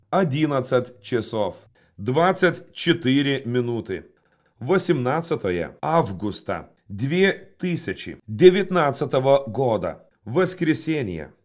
Pavyzdys Rusų kalba
• Balsu pranešamas laikas ir data